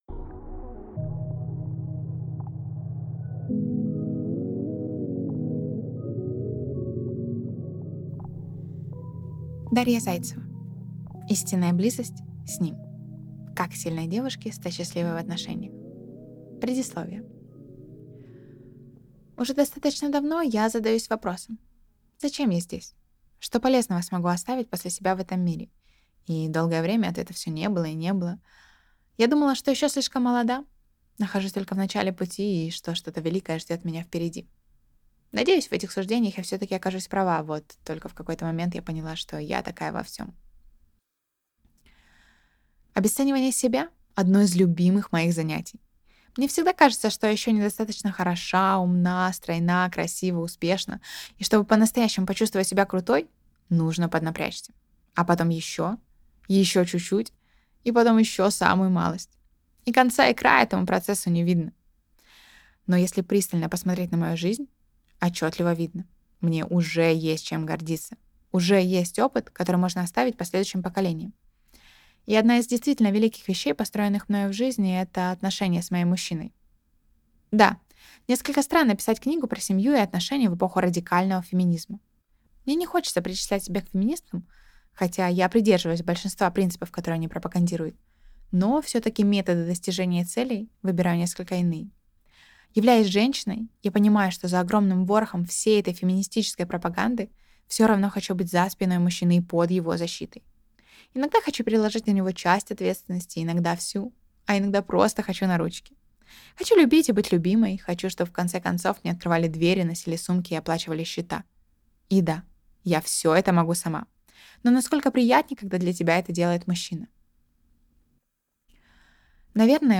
Аудиокнига Истинная близость. С ним.